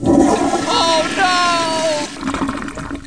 1 channel
FLUSH.mp3